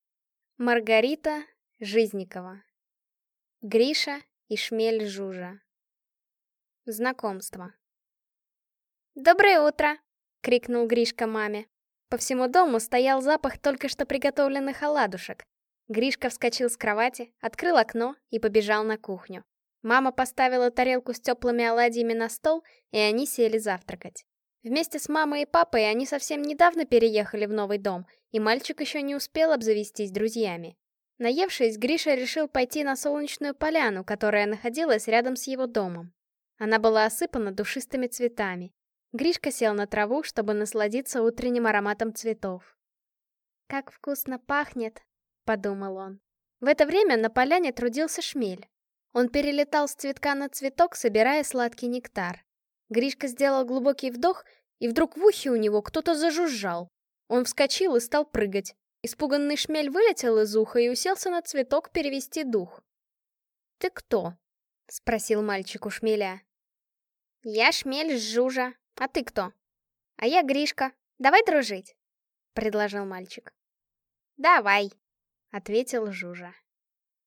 Аудиокнига Гриша и шмель Жужа | Библиотека аудиокниг